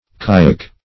Search Result for " kyack" : The Collaborative International Dictionary of English v.0.48: Kyack \Ky"ack\ (k[imac]"[a^]k), n. 1.